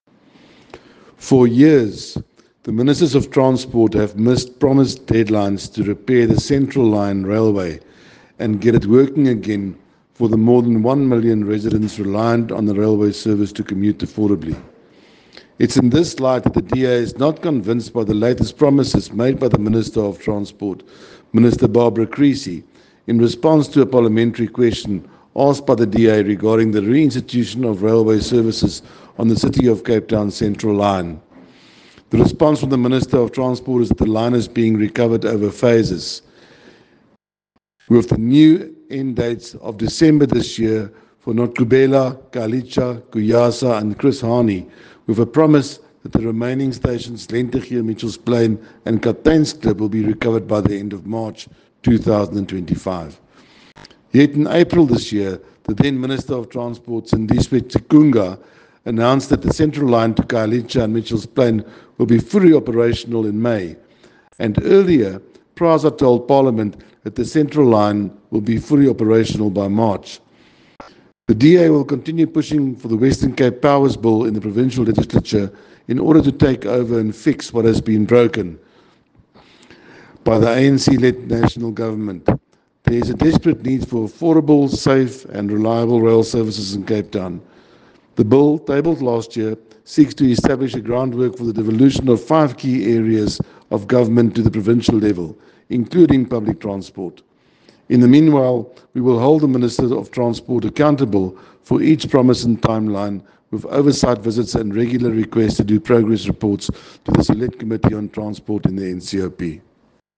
soundbite by Rikus Badenhorst MP.